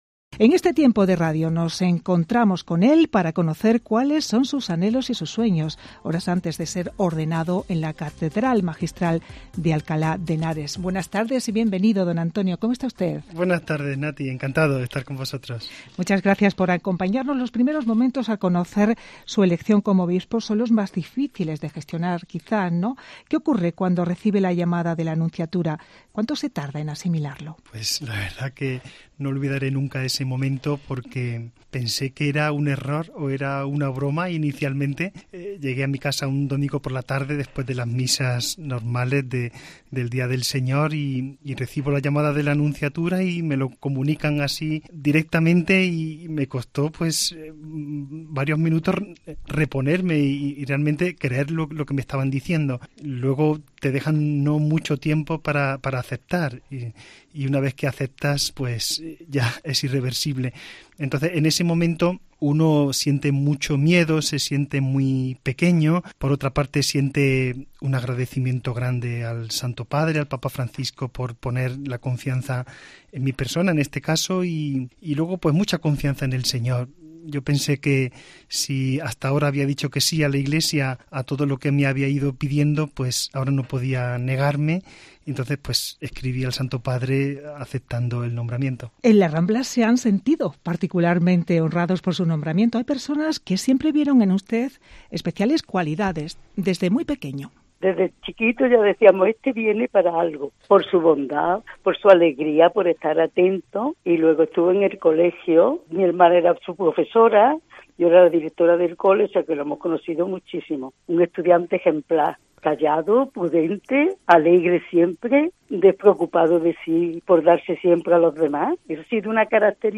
ENTREVISTA EN COPE CÓRDOBA